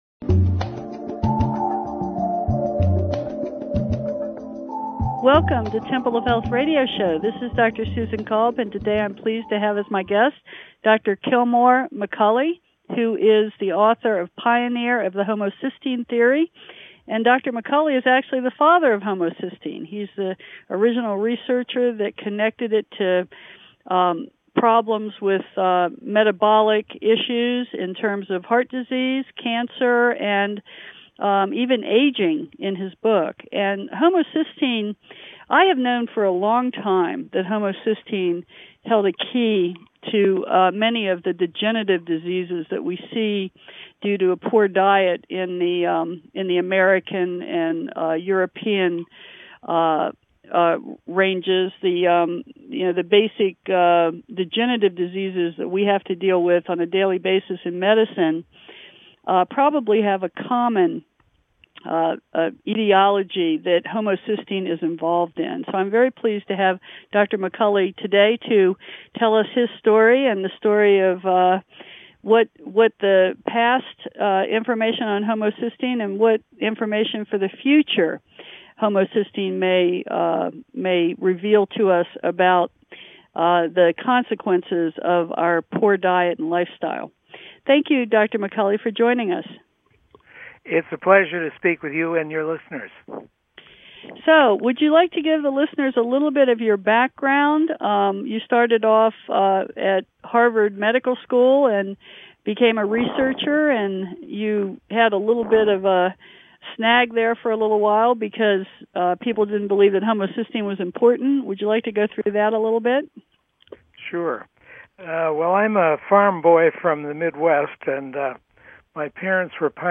Talk Show Episode
interview special guest